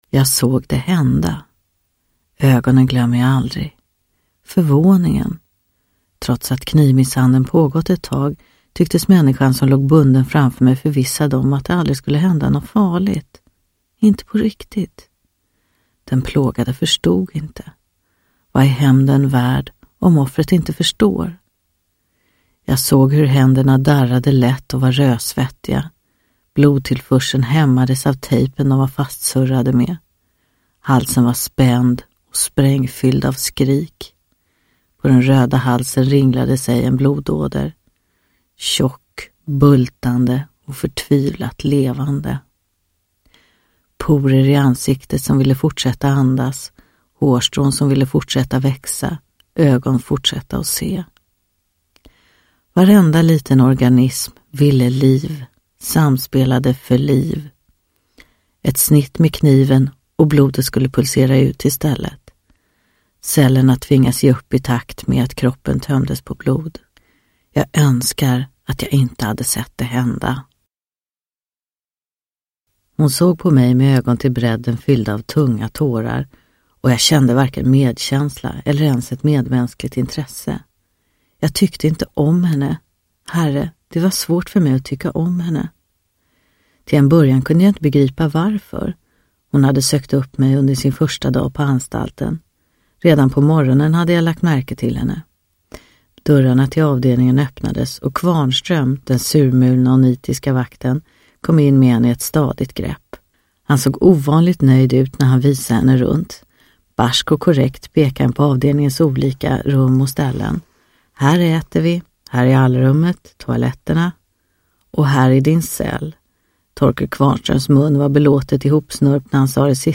Kärleken skär djupa sår – Ljudbok – Laddas ner
Uppläsare: Helena von Zweigbergk